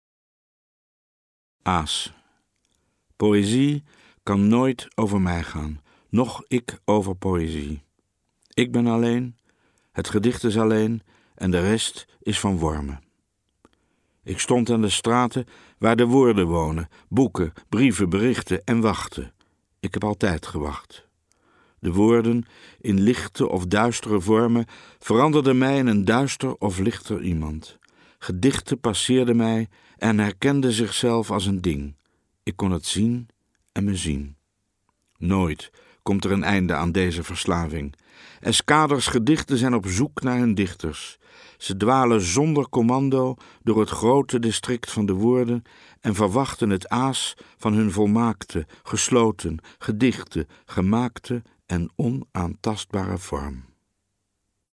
Luister naar de dichter – Escucha al poeta